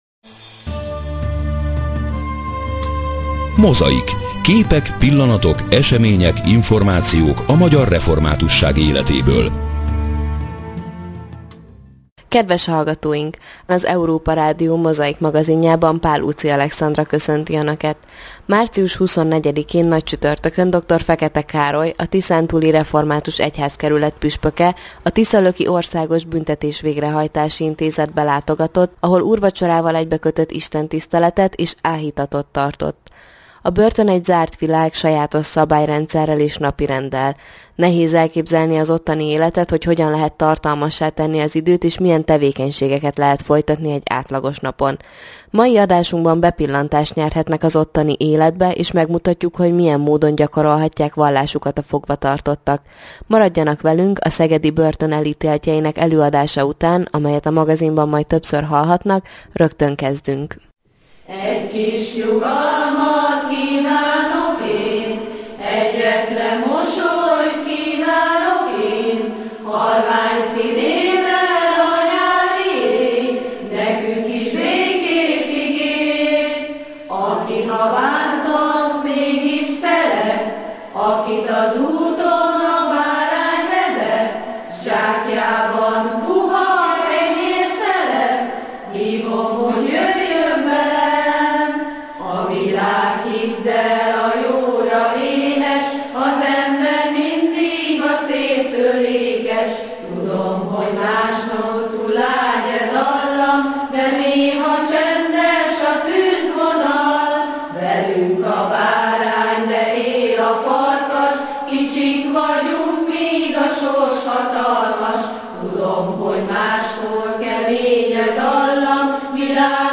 Nagycsütörtöki ökumenikus istentisztelet a tiszalöki börtönben - hanganyaggal
A kultúrteremben a szívükben ünneplőbe öltözött elítéltek várták az igehirdetést.